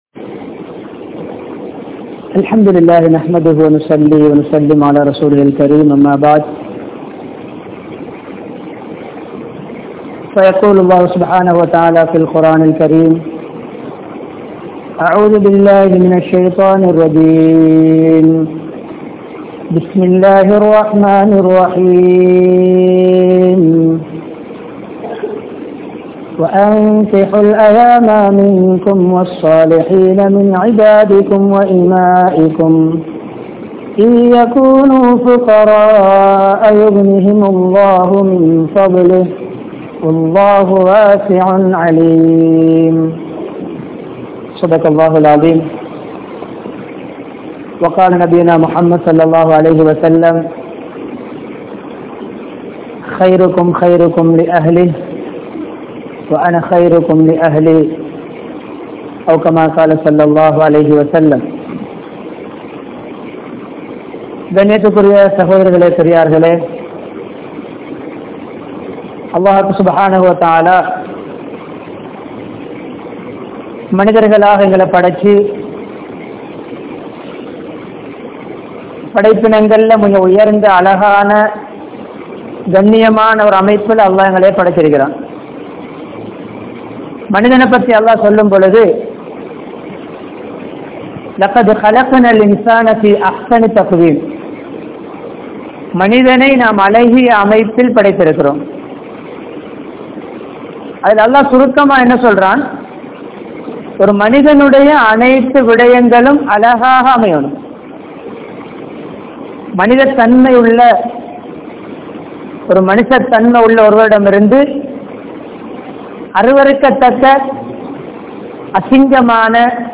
Sirantha Kanavan Yaar? (சிறந்த கணவன் யார்?) | Audio Bayans | All Ceylon Muslim Youth Community | Addalaichenai
Colombo 15, Mattakuliya, Mutwal Jumua Masjidh